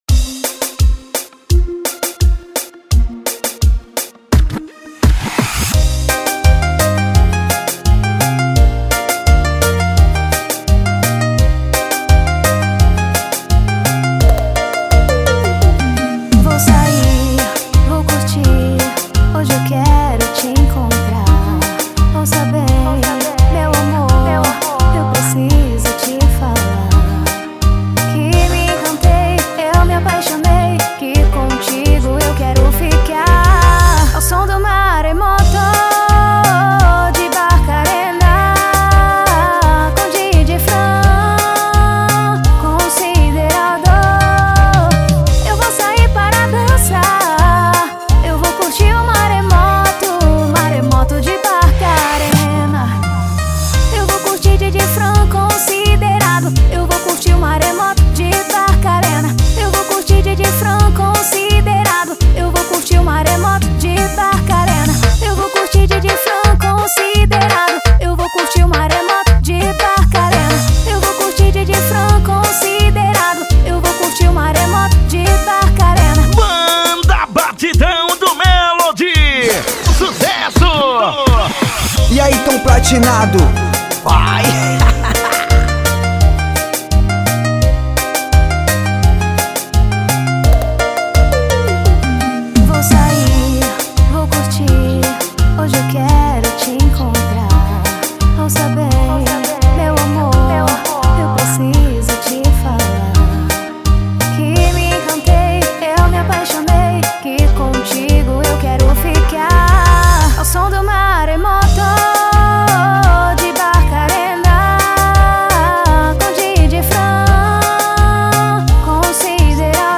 EstiloTecnobrega